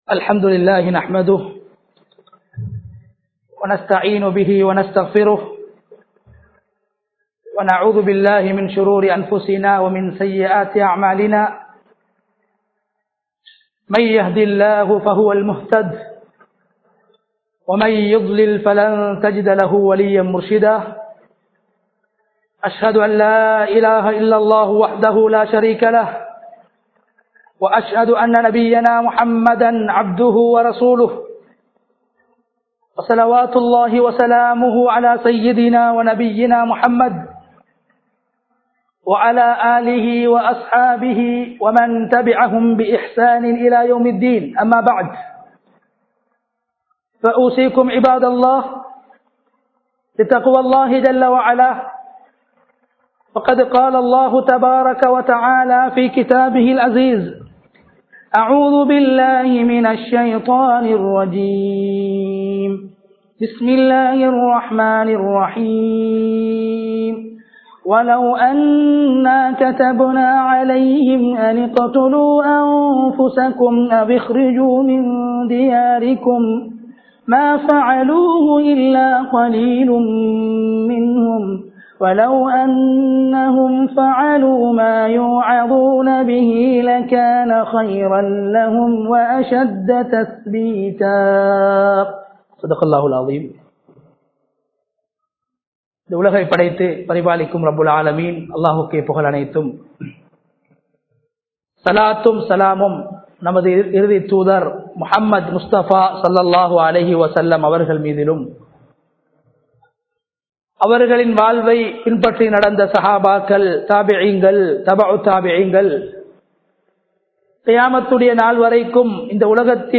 எம் நாட்டினை நேசியுங்கள் | Audio Bayans | All Ceylon Muslim Youth Community | Addalaichenai
Kandauda Jumua Masjidh